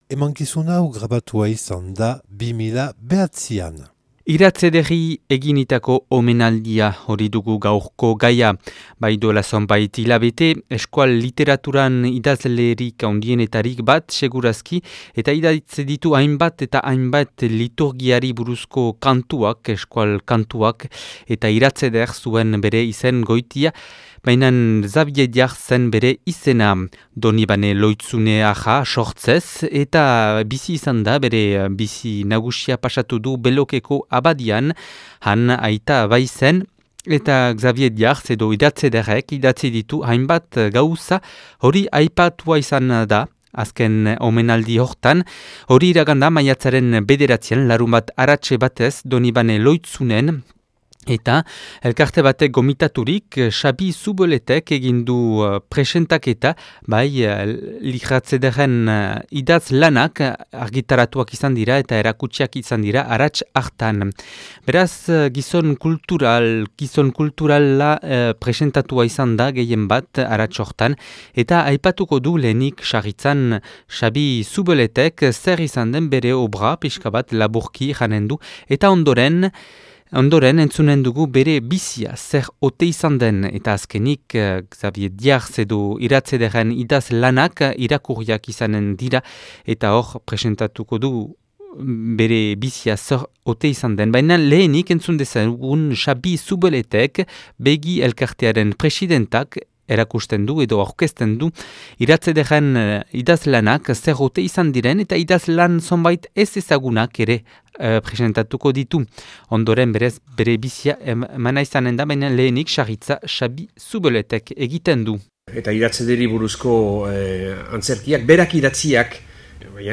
Donibane Lohitzunen 2009 urtean grabatua.